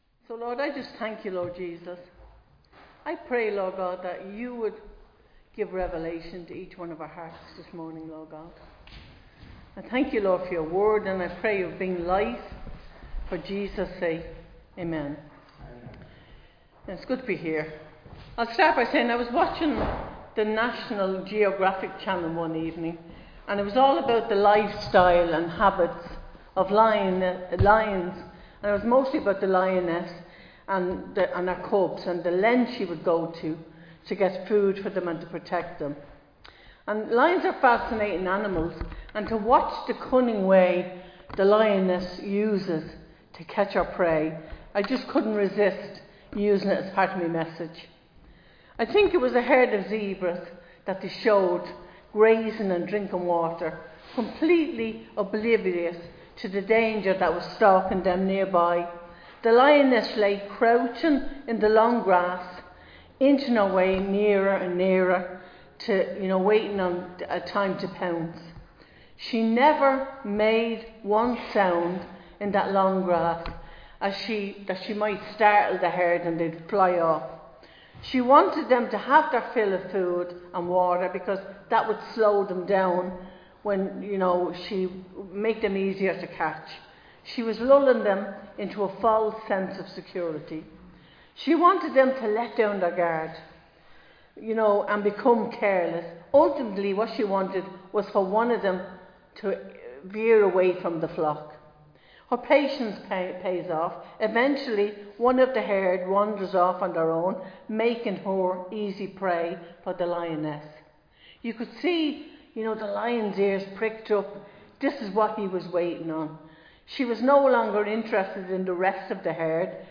Recorded live in Liberty Church on 4 May 2025